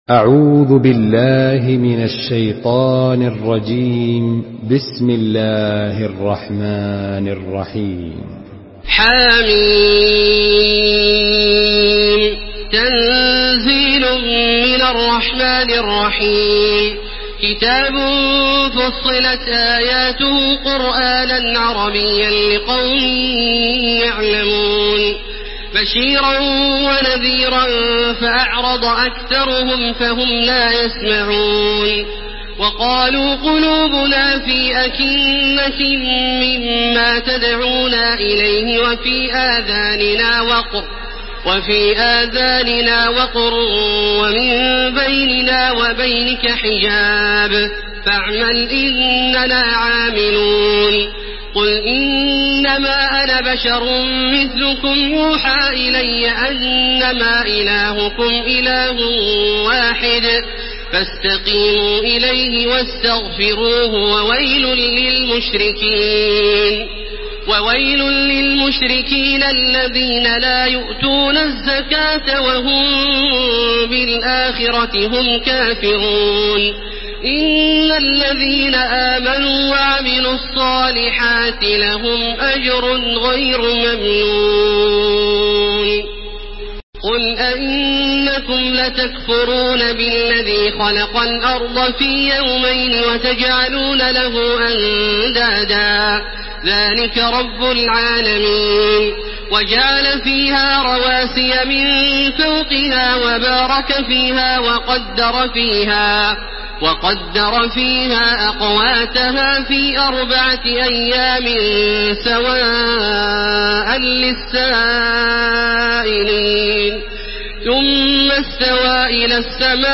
Surah فصلت MP3 in the Voice of تراويح الحرم المكي 1431 in حفص Narration
Listen and download the full recitation in MP3 format via direct and fast links in multiple qualities to your mobile phone.
مرتل